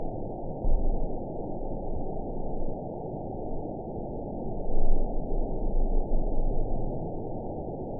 event 920150 date 02/29/24 time 23:11:12 GMT (1 month, 4 weeks ago) score 6.95 location TSS-AB10 detected by nrw target species NRW annotations +NRW Spectrogram: Frequency (kHz) vs. Time (s) audio not available .wav